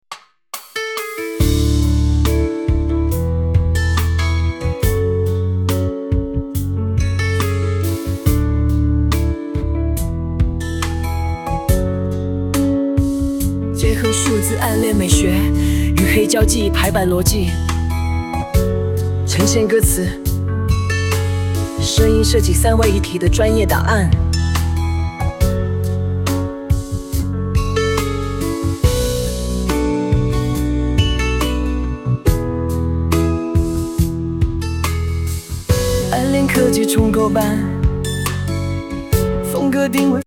**风格定位**：赛博抒情摇滚 × 故障艺术音效
人工智能生成式歌曲